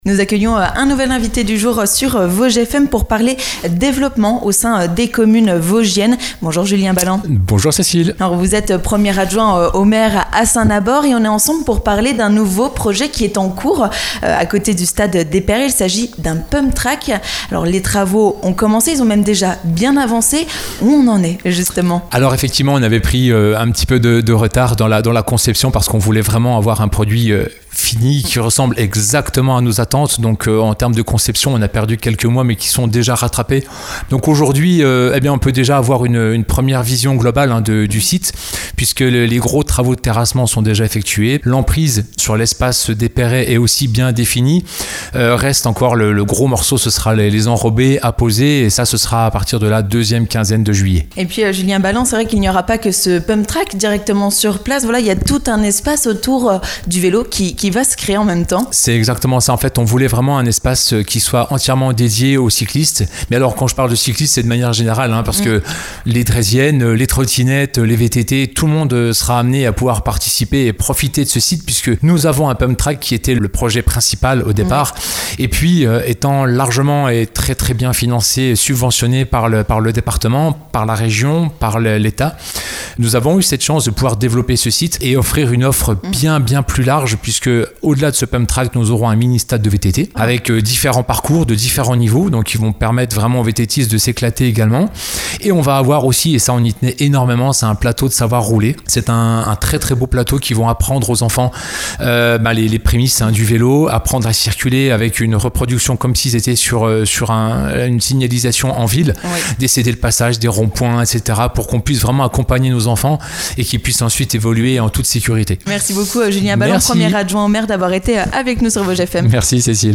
L'invité du jour
Toutes les précisions sur ce projet avec Julien Balland, premier adjoint au maire de Saint-Nabord.